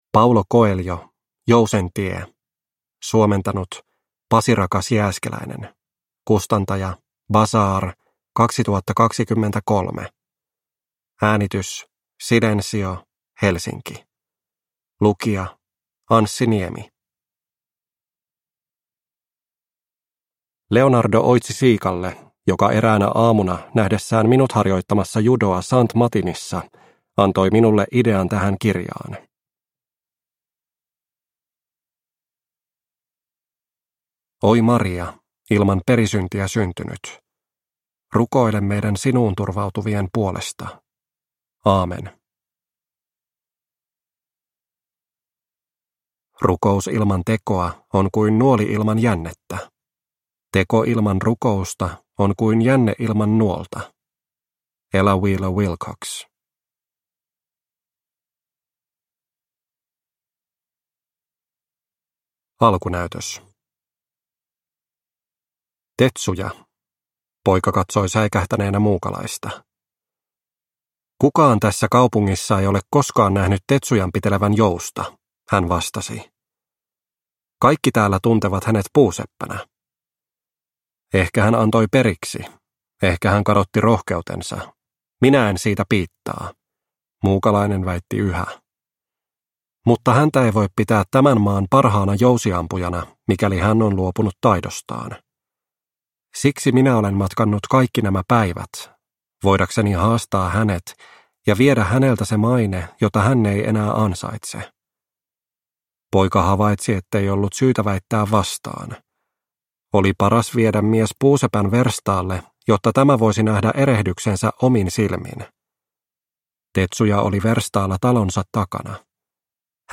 Jousen tie – Ljudbok – Laddas ner